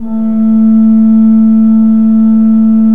Index of /90_sSampleCDs/Propeller Island - Cathedral Organ/Partition H/KOPPELFLUT M